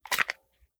9mm Micro Pistol - Dropping Magazine 002.wav